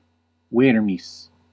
Ääntäminen
Synonyymit lumbrīcus Ääntäminen Classical: IPA: /ˈwer.mis/ Haettu sana löytyi näillä lähdekielillä: latina Käännös Ääninäyte 1. ver {m} France (Aquitaine) Canada (Montréal) Suku: m .